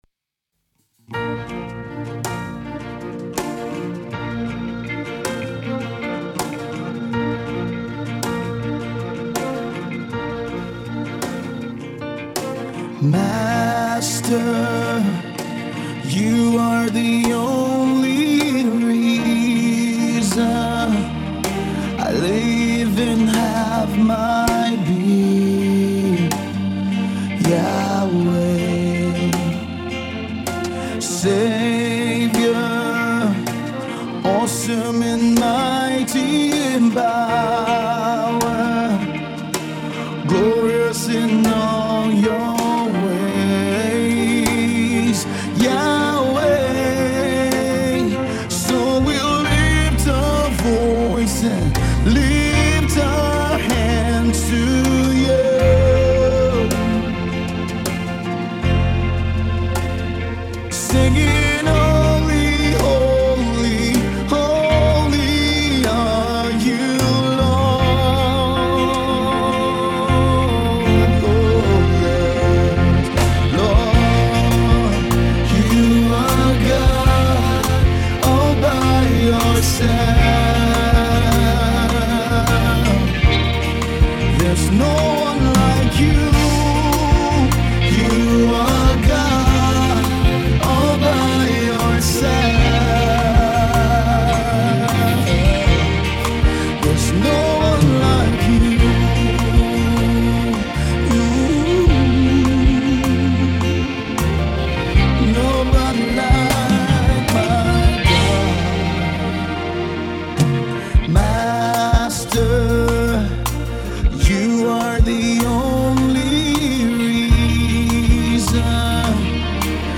soft rock ballad
recorded live